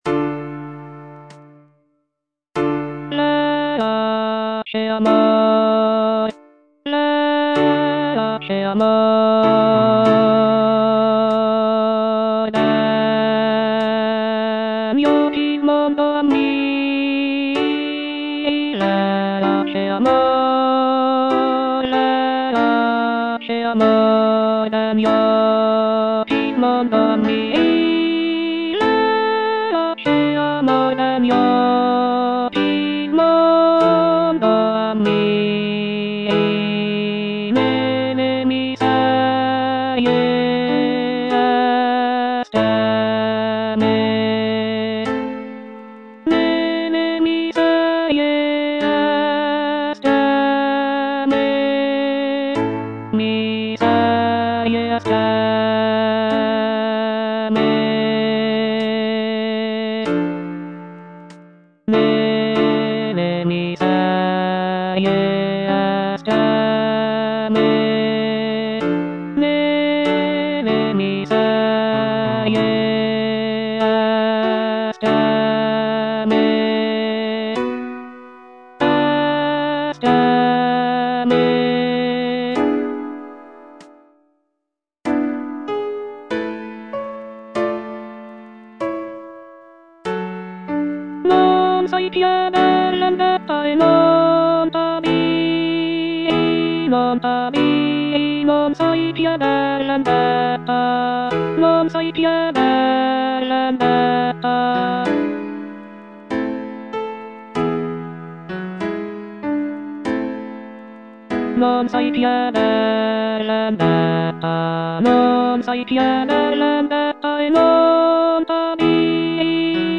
C. MONTEVERDI - LAMENTO D'ARIANNA (VERSION 2) Coro IV: Verace amor - Alto (Voice with metronome) Ads stop: auto-stop
The piece is based on the character of Ariadne from Greek mythology, who is abandoned by her lover Theseus on the island of Naxos. The music is characterized by its expressive melodies and poignant harmonies, making it a powerful and moving example of early Baroque vocal music.